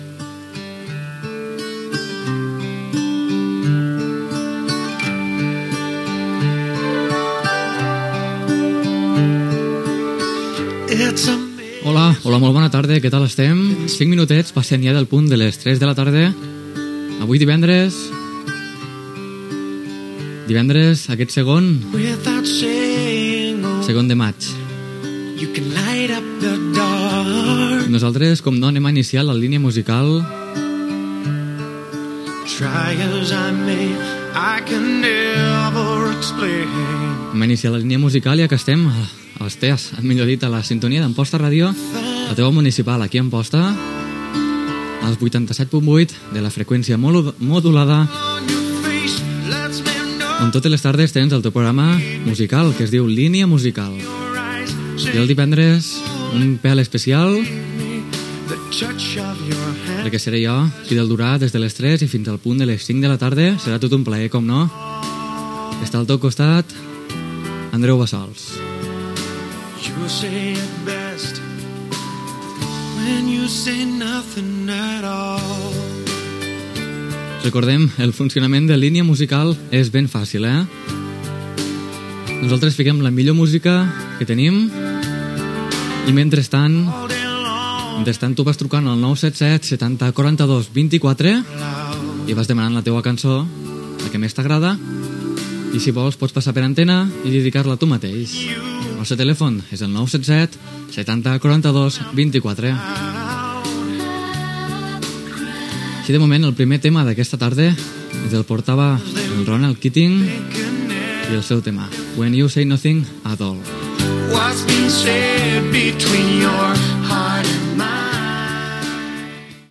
Presentació del programa
Musical
FM